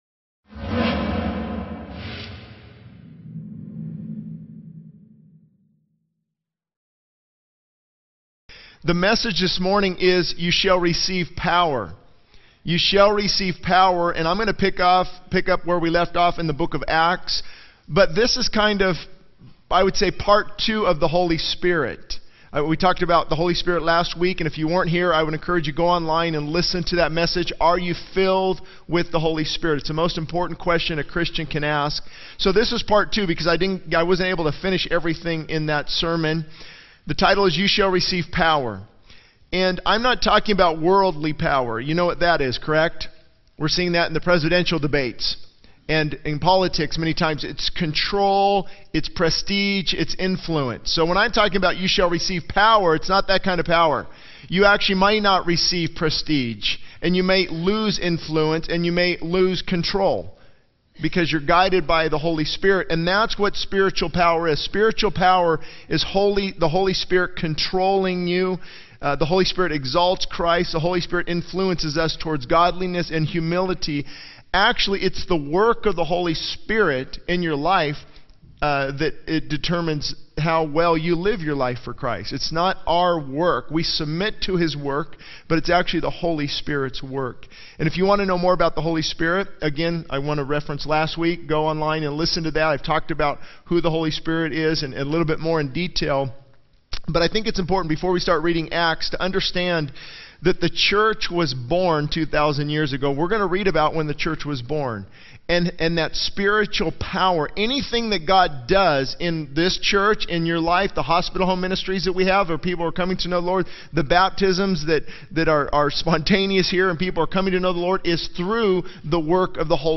SermonIndex